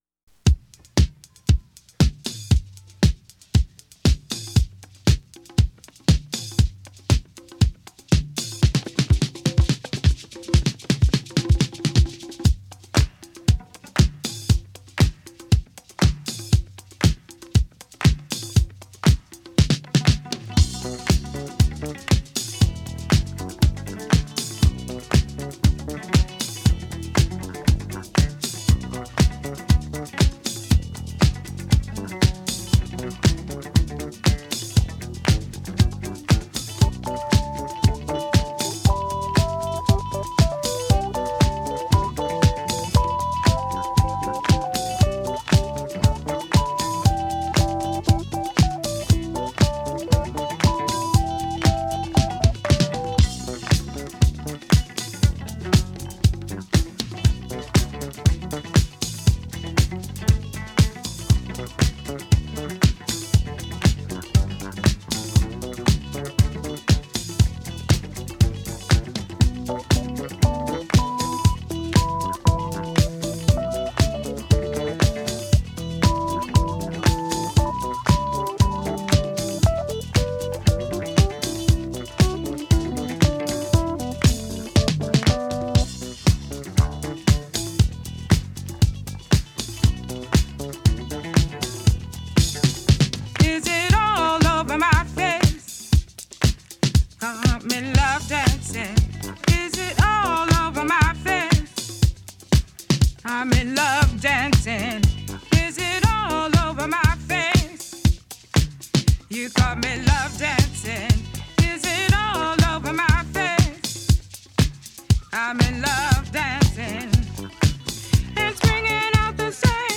Time for cosy nights in and sexy music.
Filed under bangers, classic